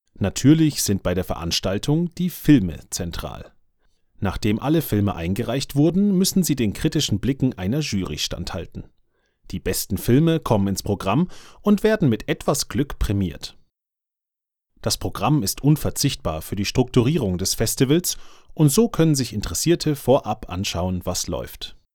Deutscher Sprecher fĂŒr Vertonungen aller Art. Meine Stimme ist tiefgehend, ruhig, klar und atmosphĂ€risch.
Sprechprobe: Industrie (Muttersprache):
Imagefilm_2.mp3